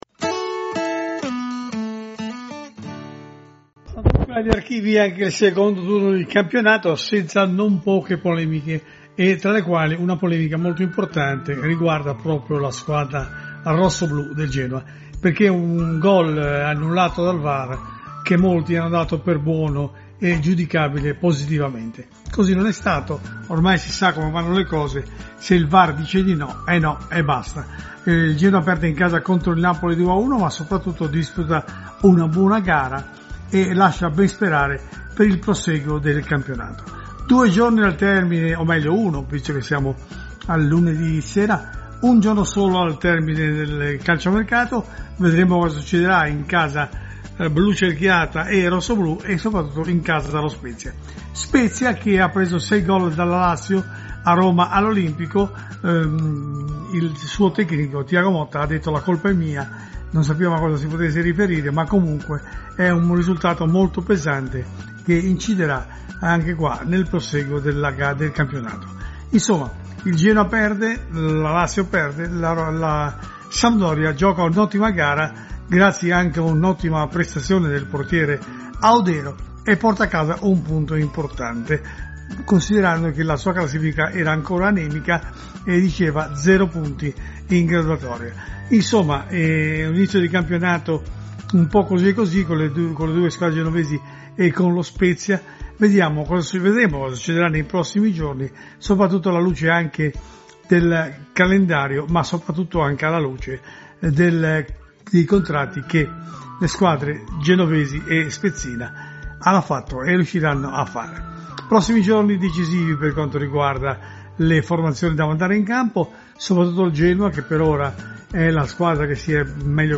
Audio | Il Commento al Campionato delle squadre liguri